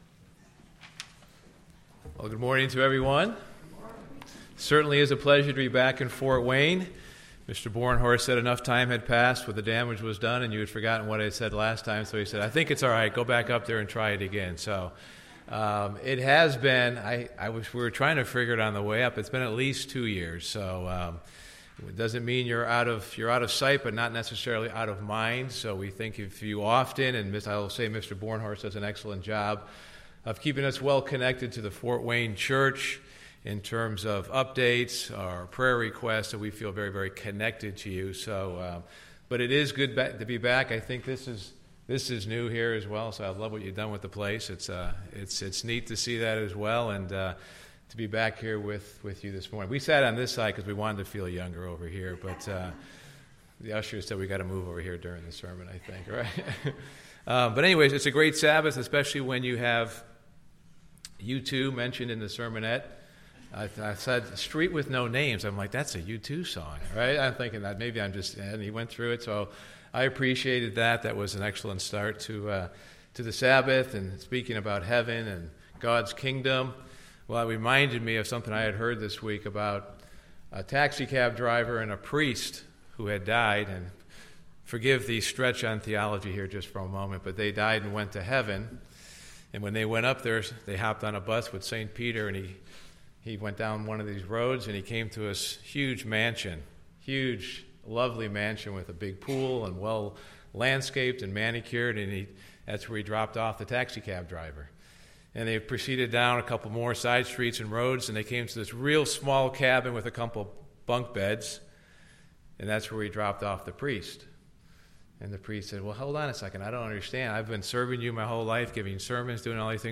This sermon gives five lessons to help us continue during the rough times in life.